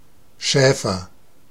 Ääntäminen
Synonyymit shepherd herd cattleman Ääntäminen : IPA : /ˈhɜrdzmən/ Haettu sana löytyi näillä lähdekielillä: englanti Käännös Ääninäyte Substantiivit 1.